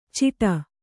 ♪ ciṭa